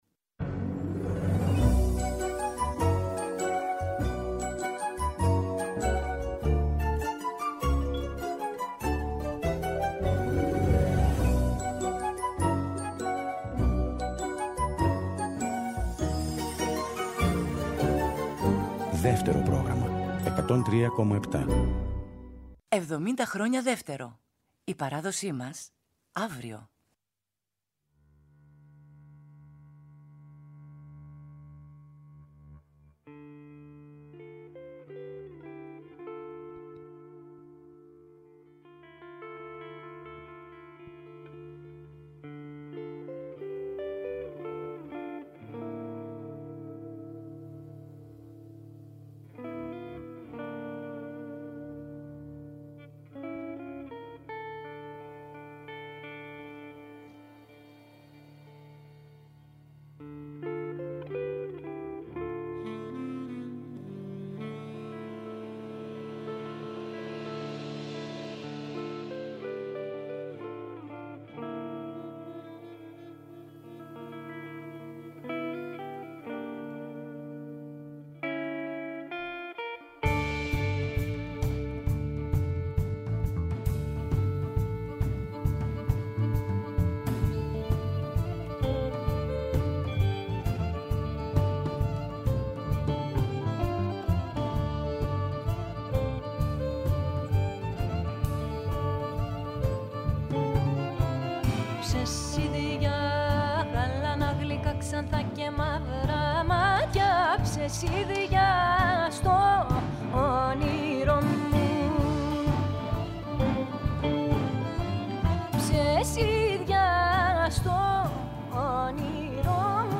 με ζωντανές ηχογραφήσεις σε Αθήνα και Θεσσαλονίκη
βιολί
ακορντεόν
κιθάρα, φωνή
κρουστά
σαξόφωνο
κοντραμπάσο